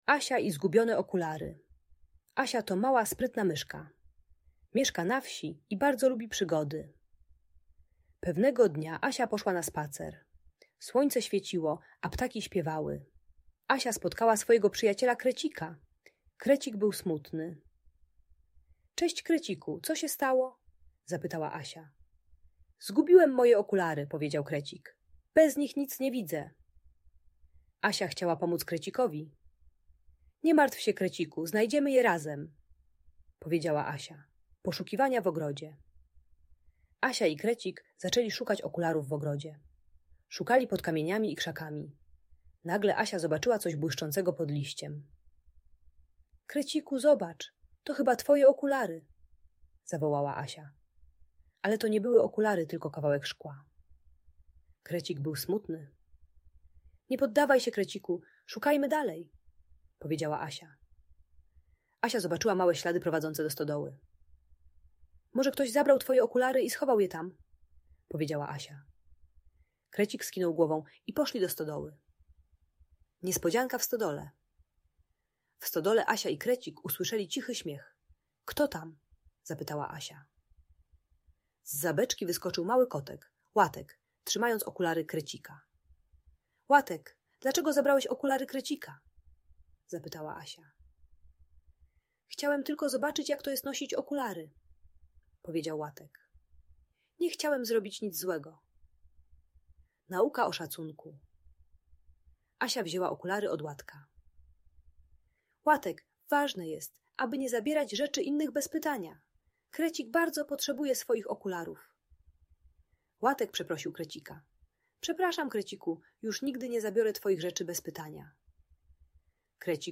Asia i Zgubione Okulary: Przygoda sprytnej myszki - Audiobajka dla dzieci